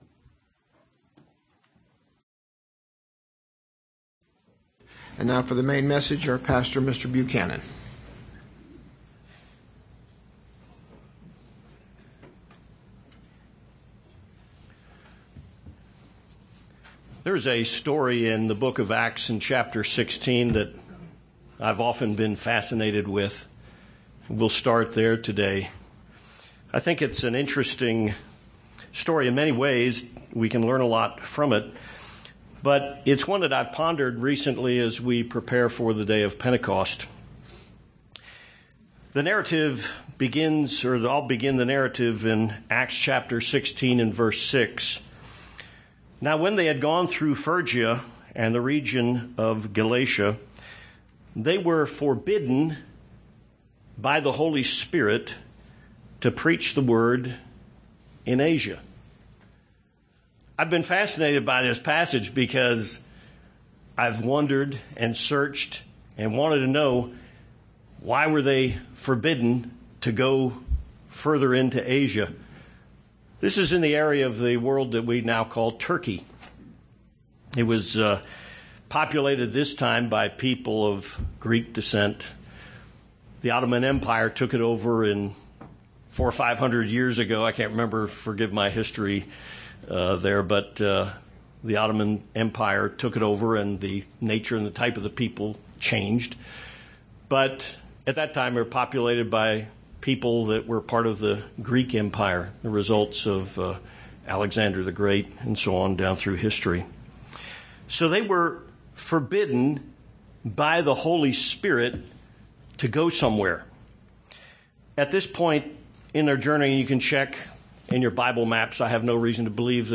The Bible is filled with examples of God's people being led by the power of the Holy Spirit. This sermon presents three points on reliance and dependence on God's Spirit.